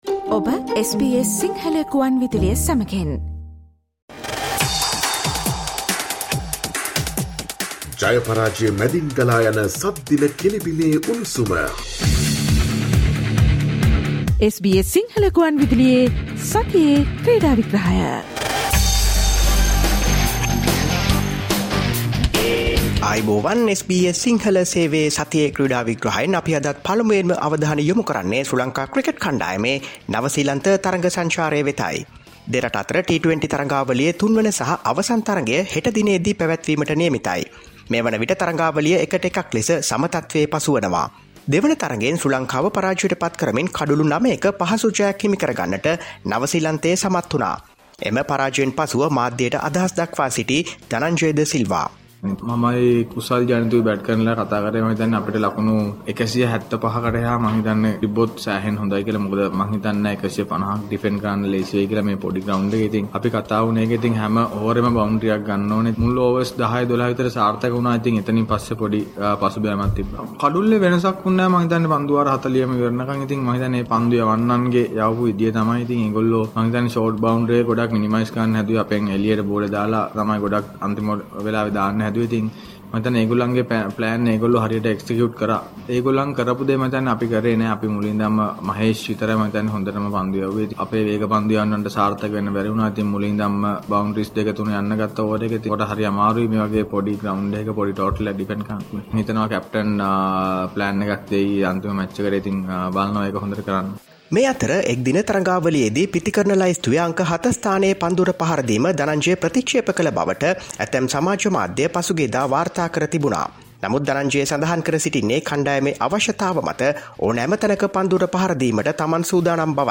Listen to the SBS Sinhala Radio weekly sports highlights every Friday from 11 am onwards Here are the last week’s sports highlights